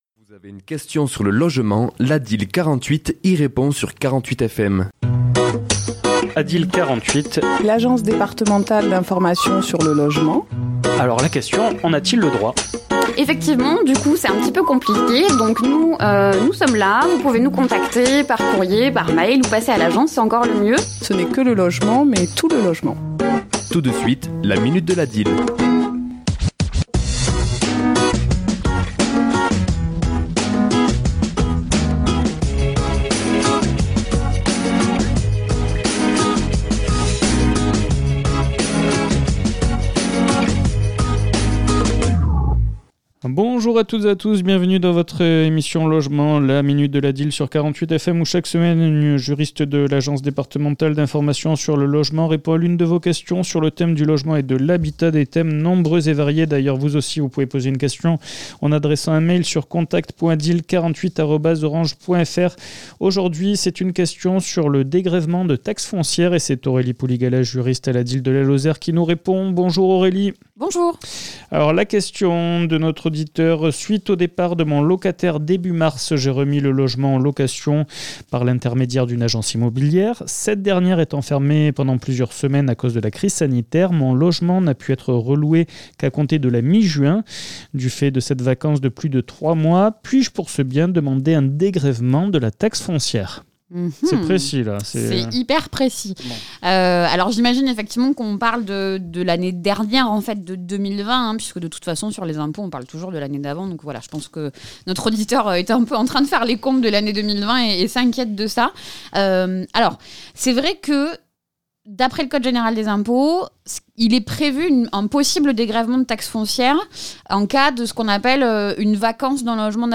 Chronique diffusée le mardi 23 mars à 11h00 et 17h10